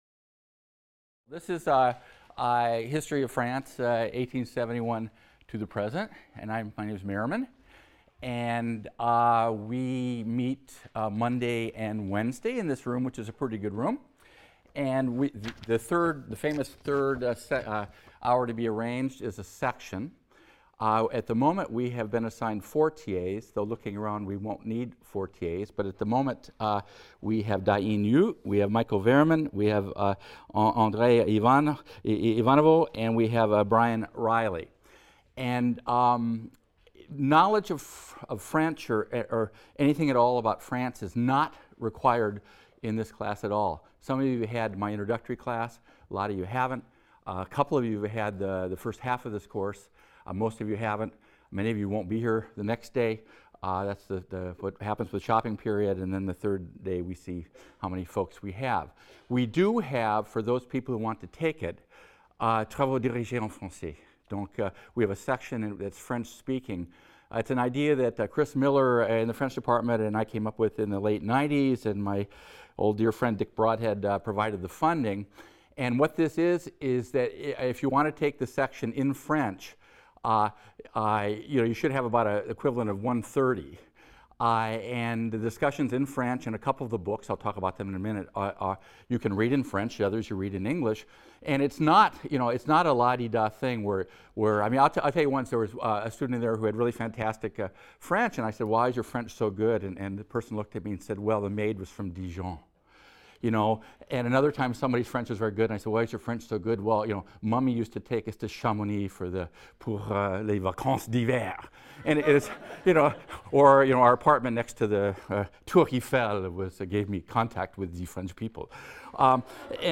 HIST 276 - Lecture 1 - Introduction | Open Yale Courses